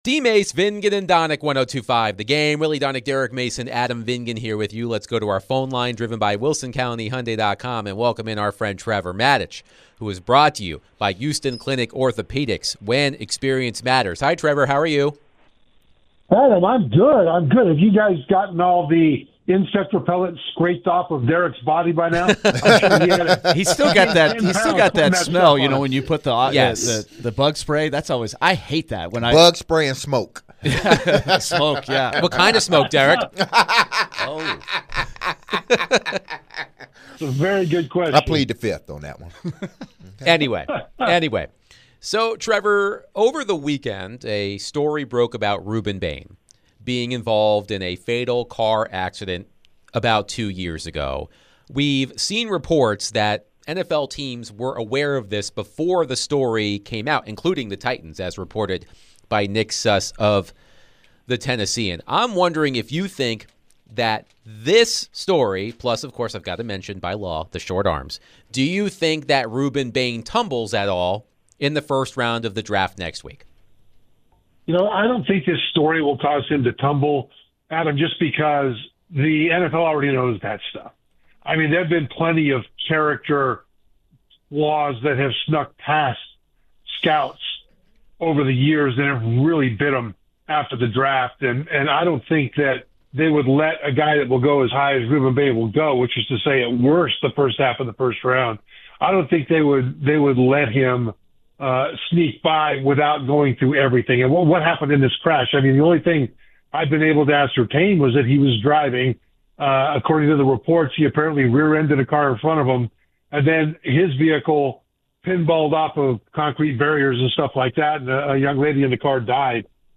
ESPN NFL Analyst Trevor Matich joined to discuss all things Titans, NFL Draft, Rueben Bain, and more